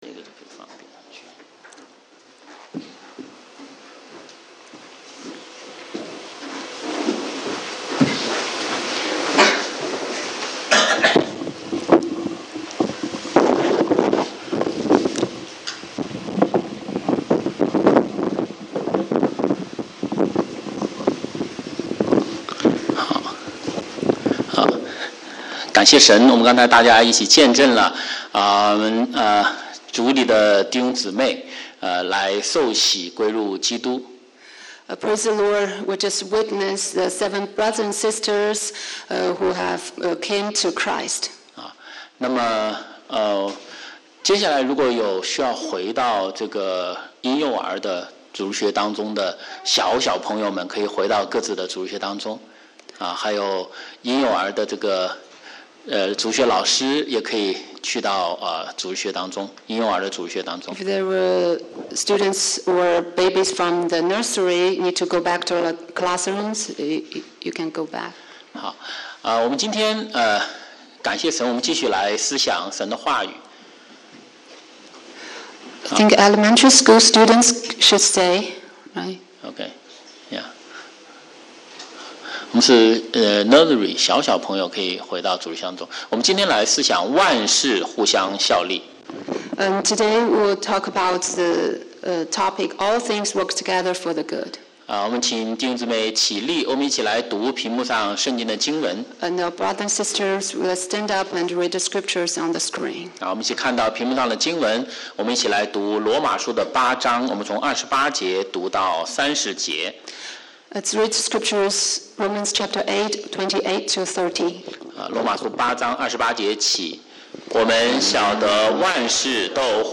华语主日崇拜讲道录音